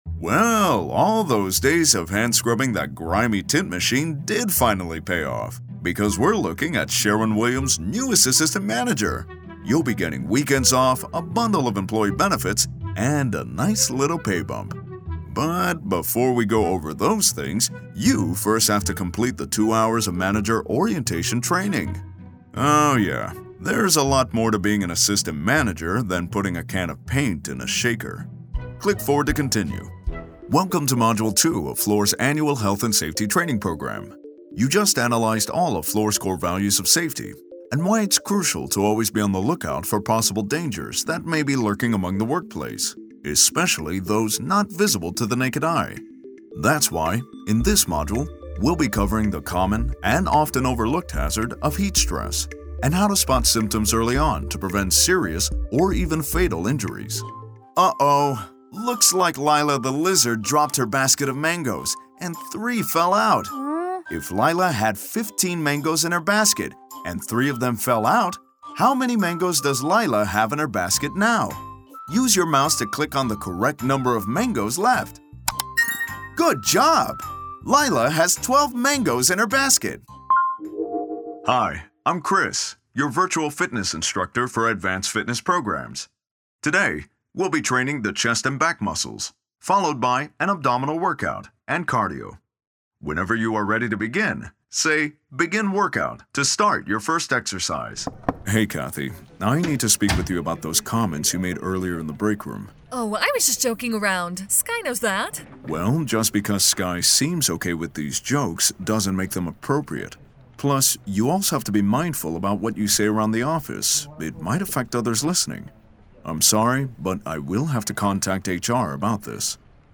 Teenager, Young Adult, Adult, Mature Adult
e-learning